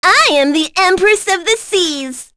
Miruru-Vox_Skill5_b.wav